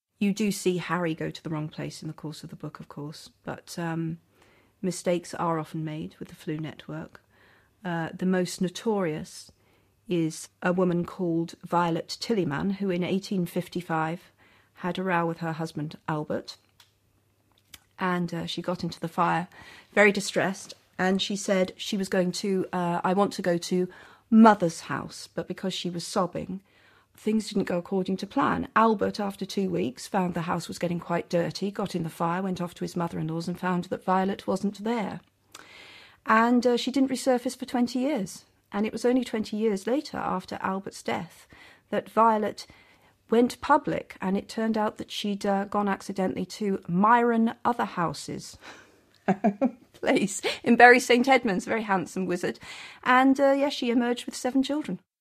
ฟัง เจ.เค.โรว์ลิ่ง บอกเล่าเรื่องราว ของ ไวโอเล็ต ทิลลี่แมน